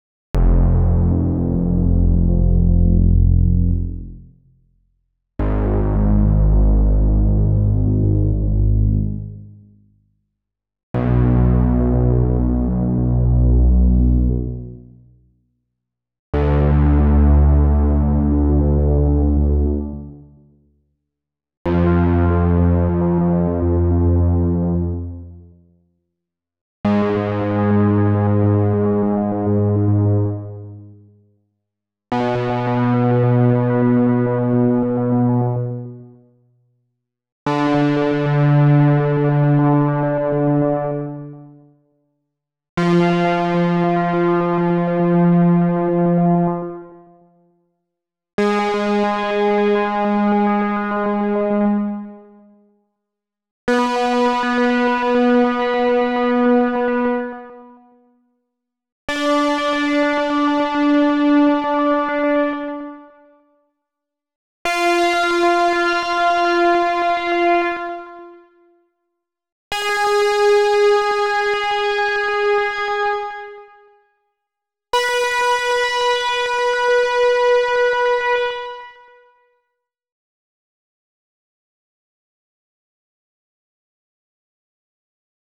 39_FatSample+HoldPPG_F+3_1-4.wav